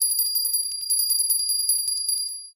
BellSmallGold.ogg